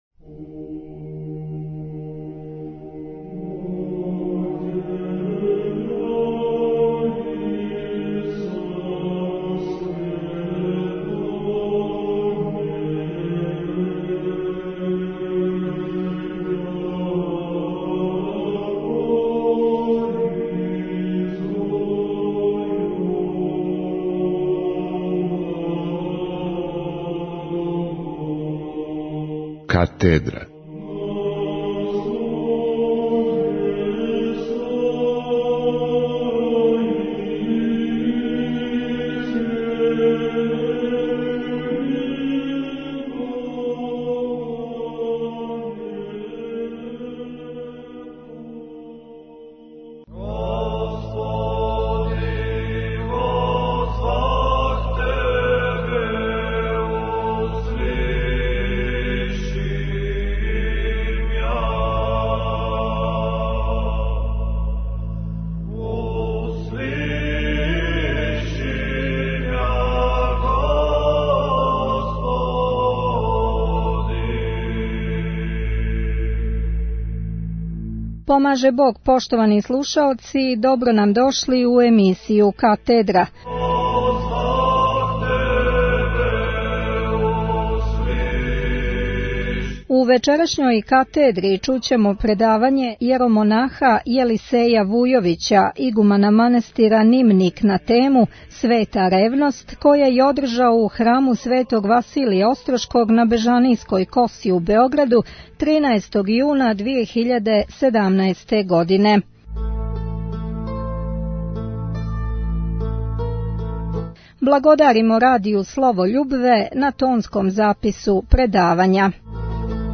Овогодишњи „Трг од ћирилице“ свечано је отворио Високопреосвећени архиепископ цетињски митрополит црногорско-приморски г. Амфилохије бесједом о Светом Петру Другом Петровићу Његошу, Ловћенском Тајновидцу и јубилеју 170 година „Горског вијенца”.